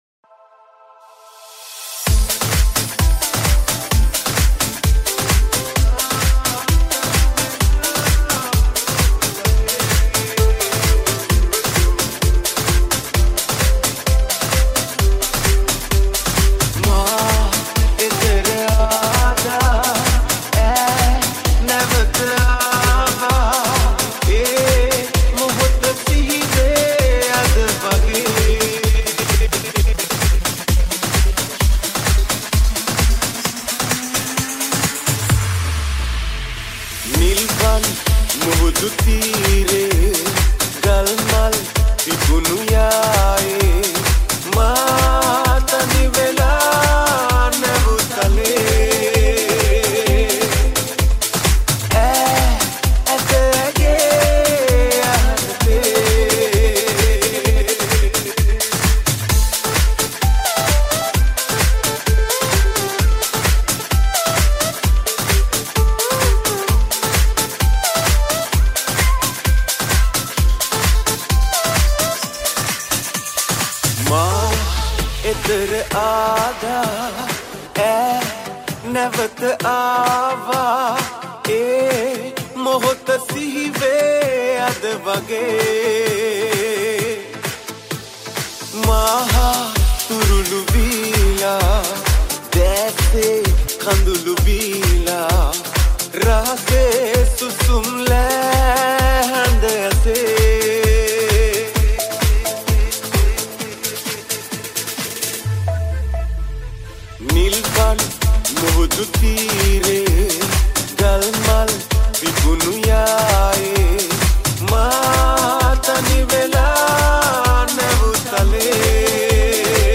House Dance Remix